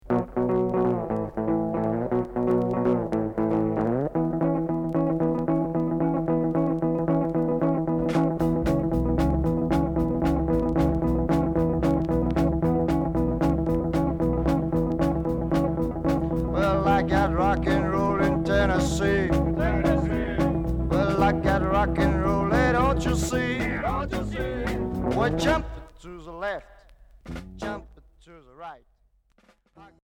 Rockabilly Unique 45t retour à l'accueil